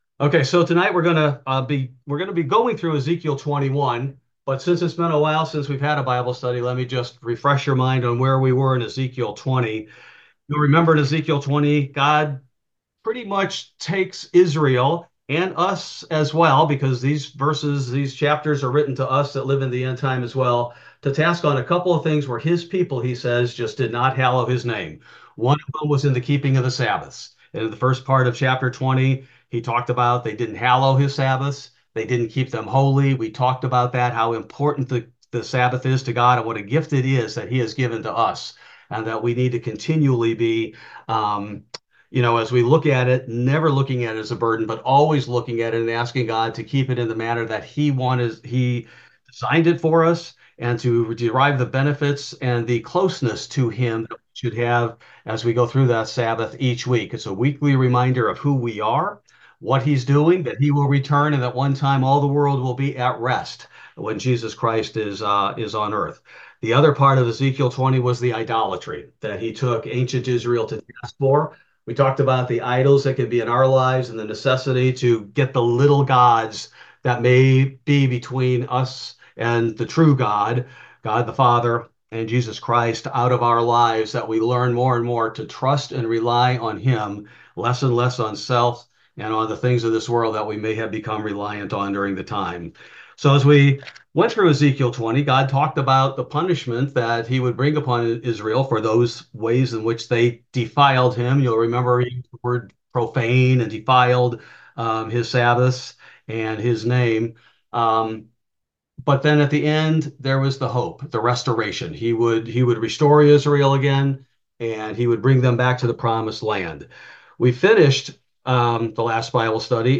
This verse by verse Bible Study primarily covers the Book of Ezekiel, Chapter 21